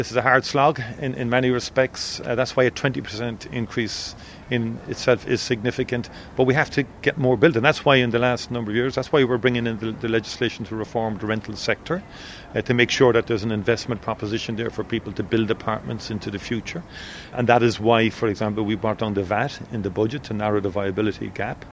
Taoiseach Micheál Martin is welcoming the figures, but acknowledges the country is playing catch-up in terms of house building………….